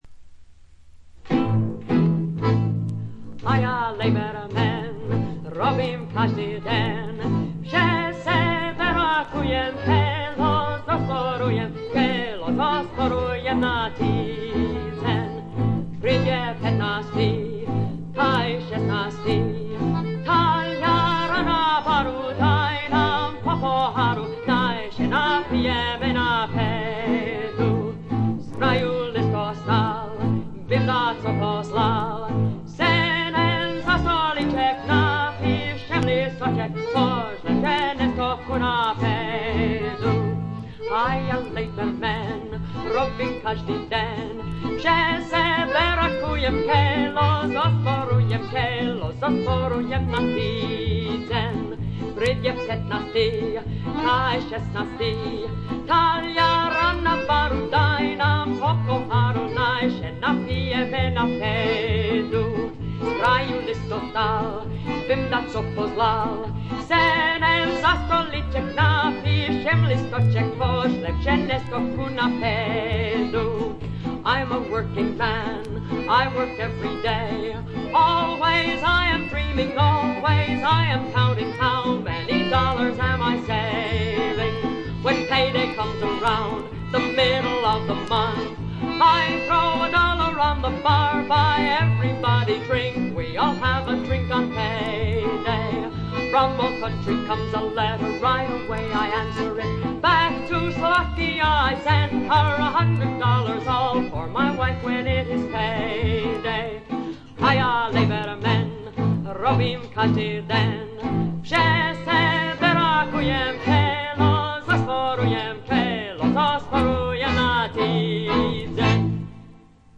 軽微なバックグラウンドノイズにチリプチ少し。
魅力的なヴォイスでしっとりと情感豊かに歌います。
試聴曲は現品からの取り込み音源です。
Recorded At - WDUQ, Pittsburgh, PA